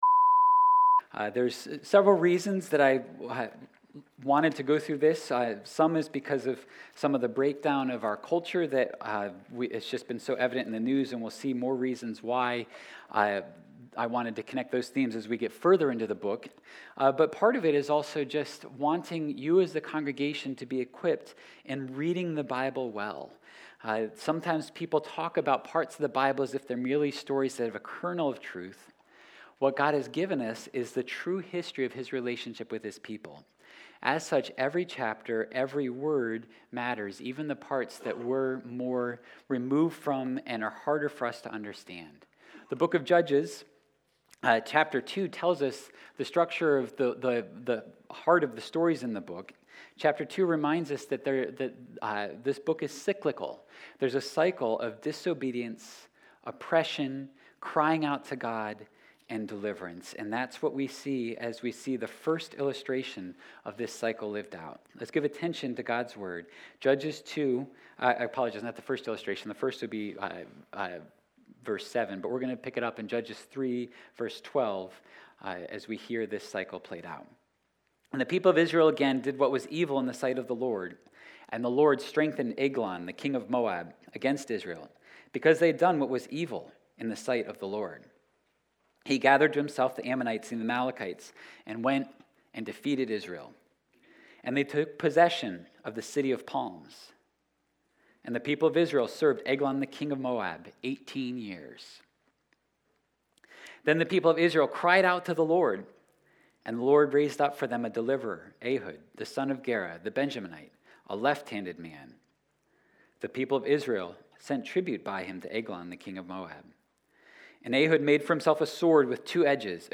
Sunday Worship – October 9 of 2022 – Shocking Details and Sure Deliverance